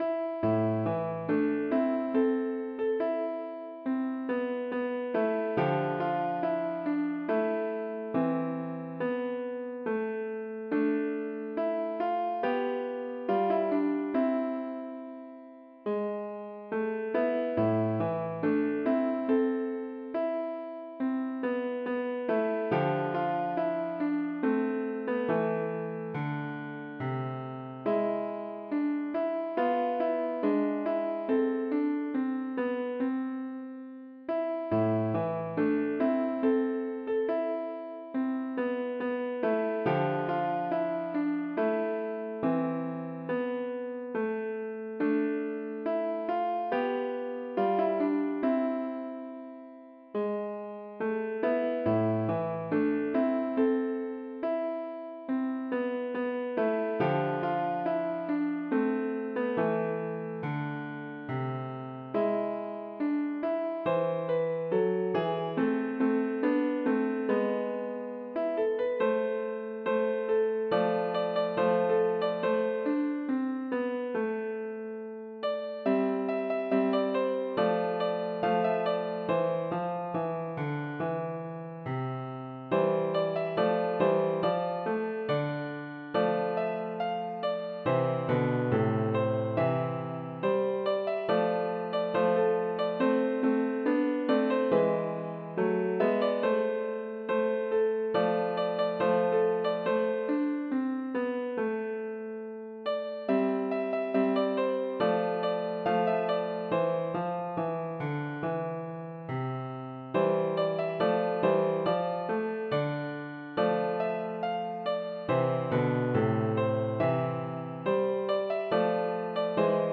in Am
Based on the full notation version